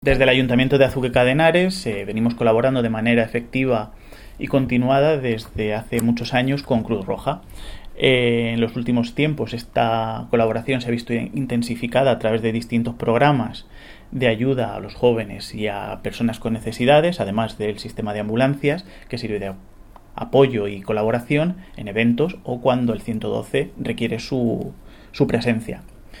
Declaraciones del concejal Miguel Óscar Aparicio 1